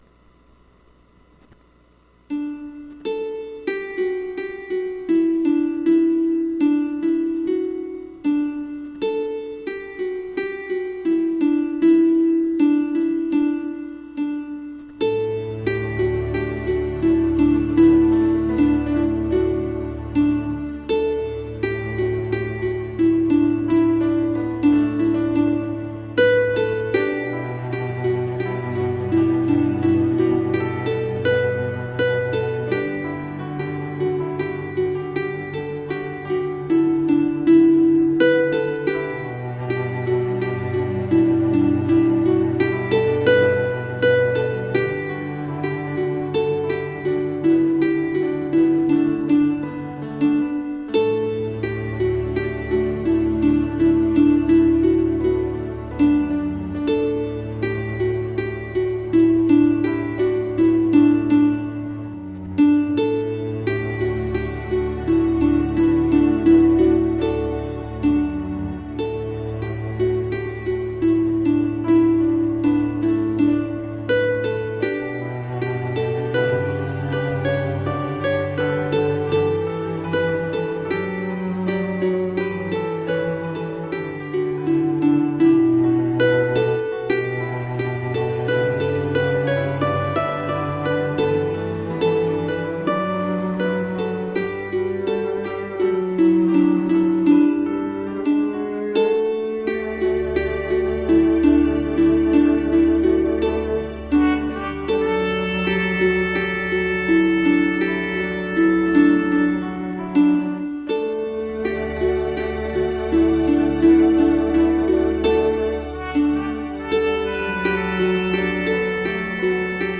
Classical and jazz compositions of my own:
It features an African Kora backed up by piano, cello, strings and finally trumpet.